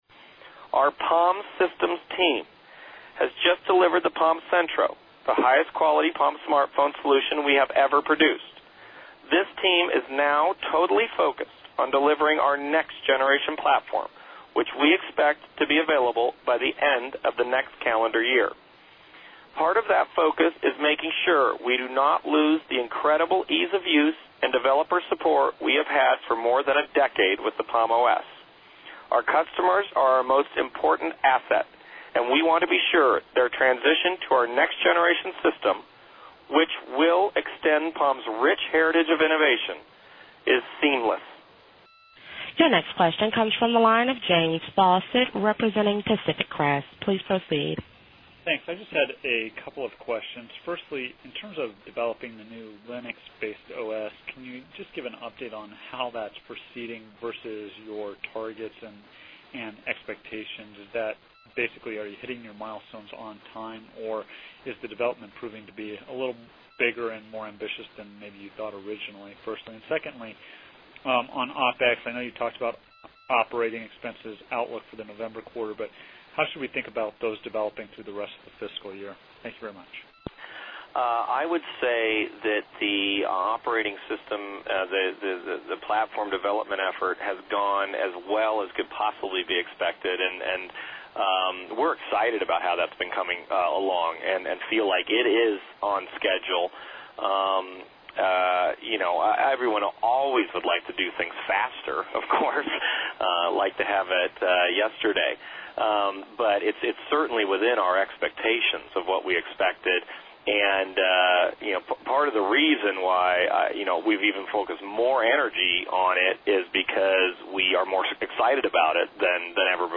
Palm OS Investor Call - MP3 recording of Ed Colligan speaking about death of palm os, and future devices such as the infamous Palm Foleo.